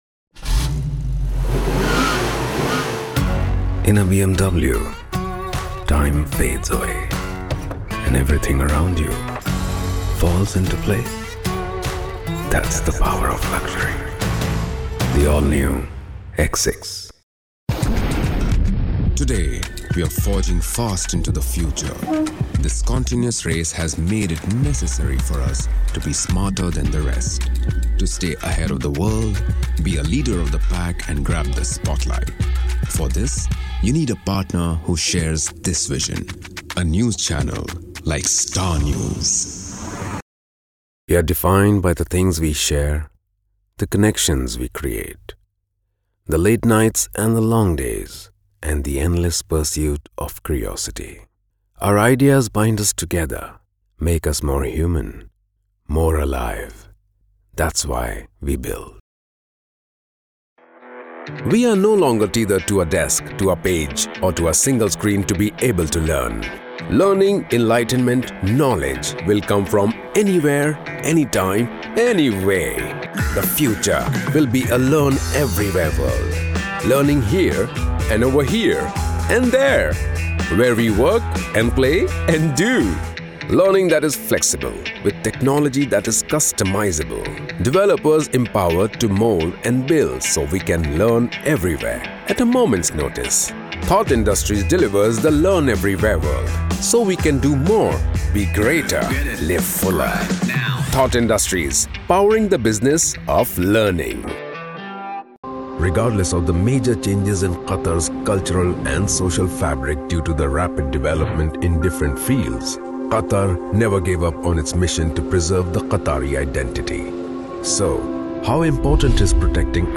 English Demos
Neutral / Indian/ British/ Arabic / Russian
Middle Aged
Equipped with a professional home studio, Passionately enjoy voice over projects of all kinds,